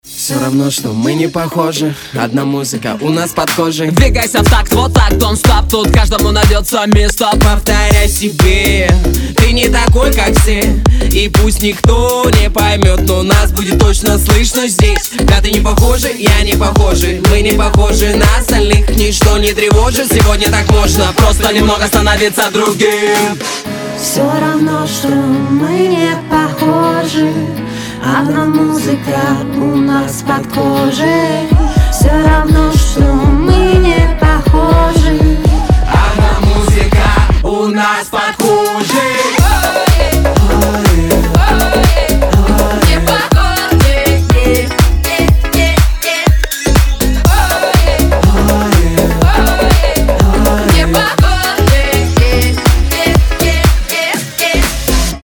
• Качество: 320, Stereo
забавные
веселые
заводные
качает